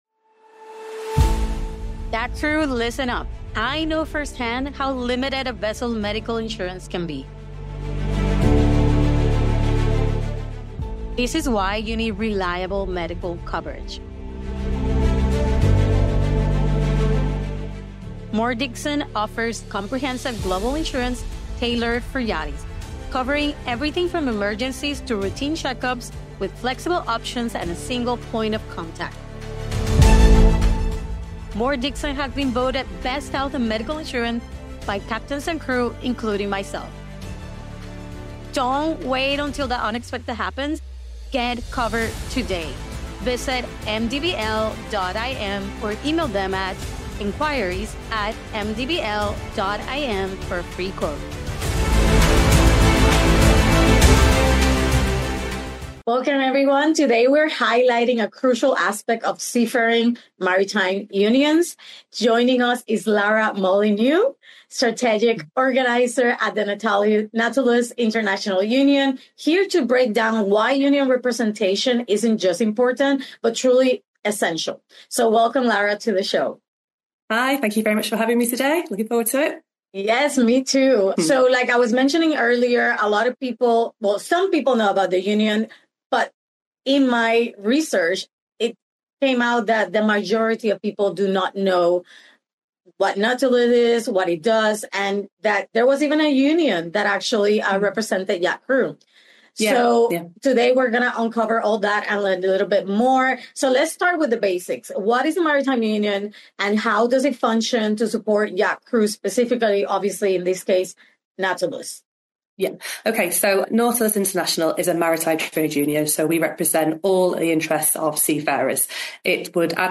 Why Yacht Crew Need Union Representation: An In-Depth Discussion with Nautilus Int. | UNCENSORED